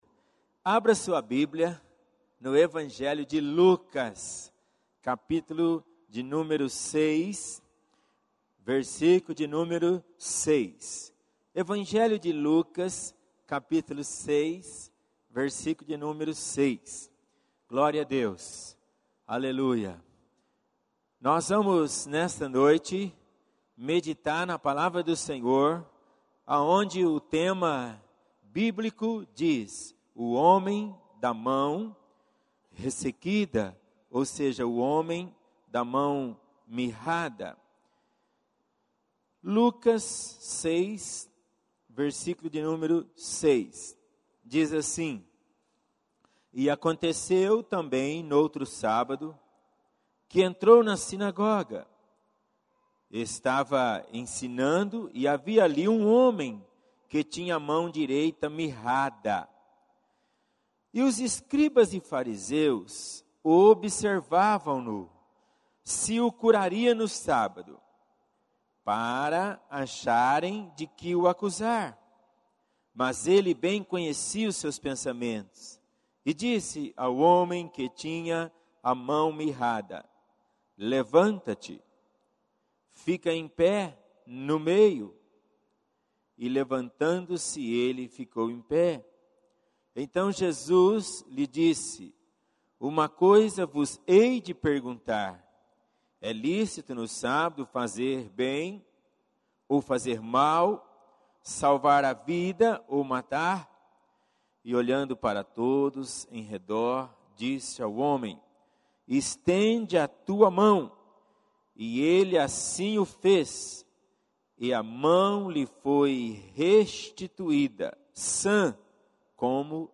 em Culto da Fé